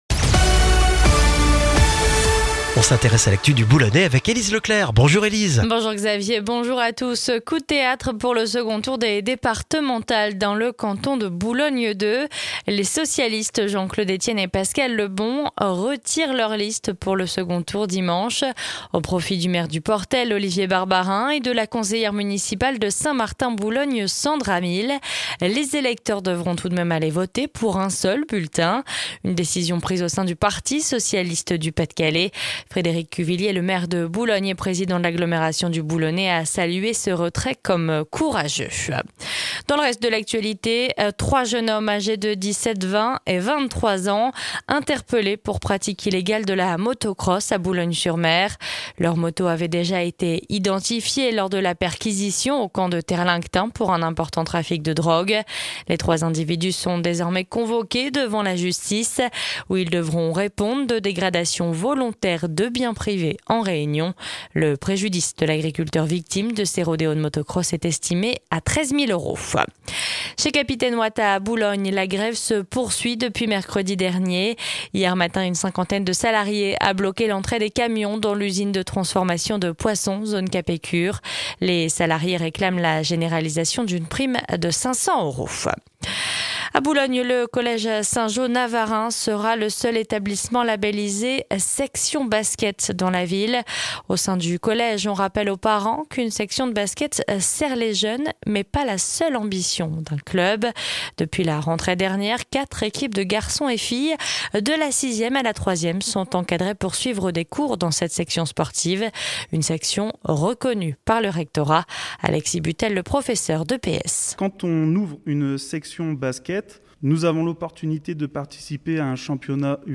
Le journal du mardi 22 juin dans le Boulonnais